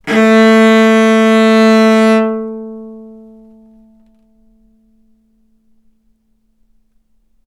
vc-A3-ff.AIF